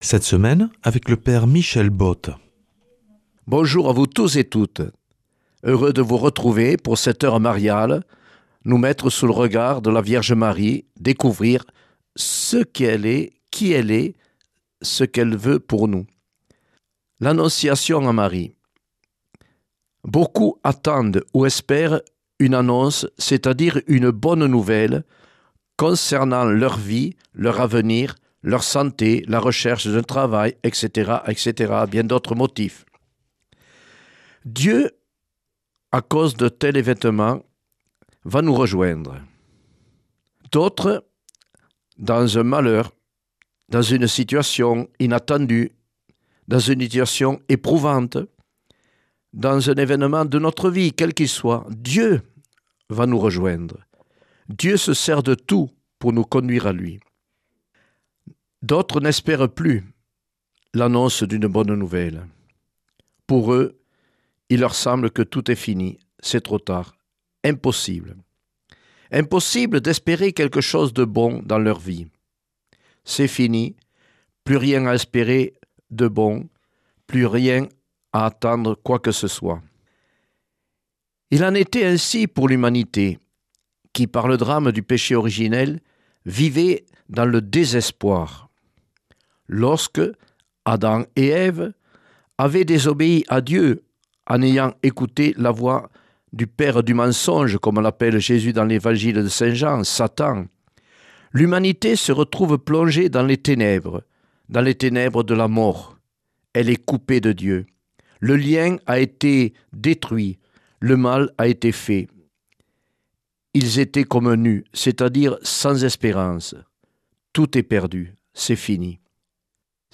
Enseignement Marial